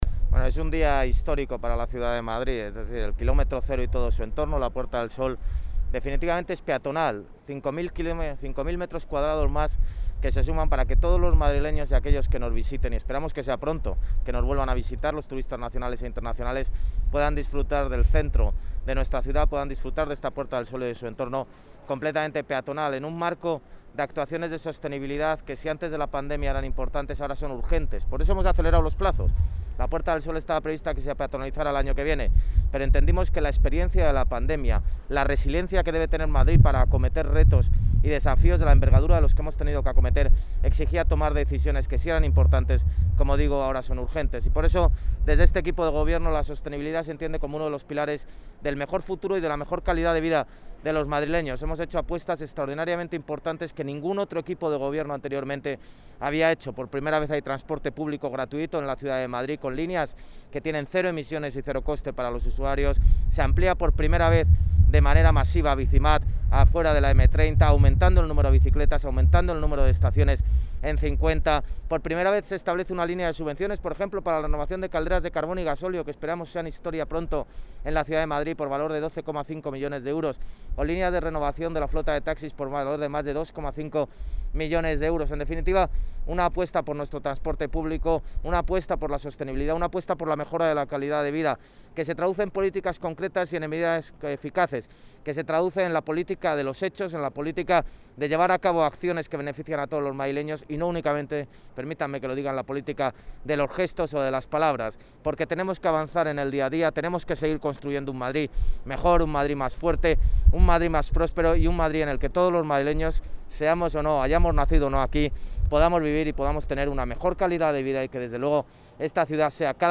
Nueva ventana:José Luis Martínez-Almeida, alcalde de Madrid
(AUDIO) DECLARACIONES ALCALDE SOBRE PEATONALIZACION DE SOL.mp3